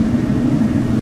techage_turbine.ogg